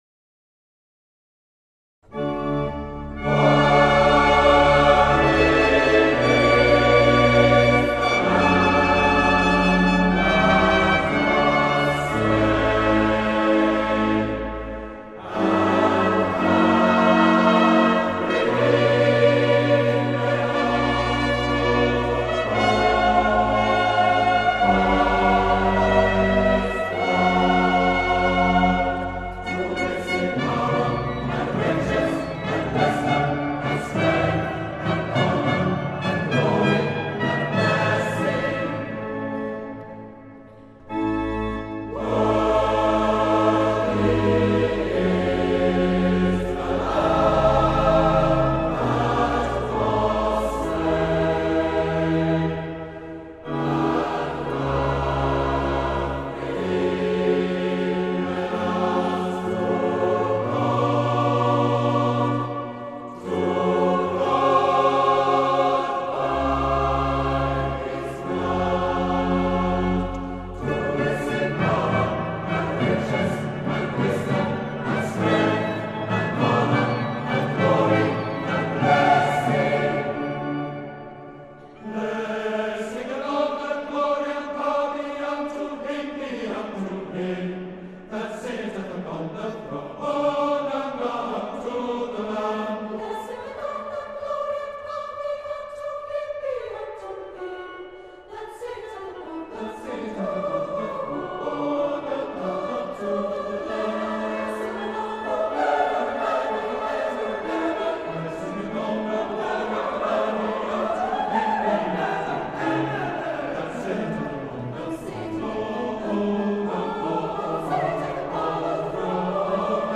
Taster (organ accompaniment only) for a live full orchestral version of Handel's Messiah.
Worthy Is The Lamb - Messiah - Eger - Leeds Philharmonic Society.mp3